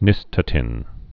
(nĭstə-tĭn)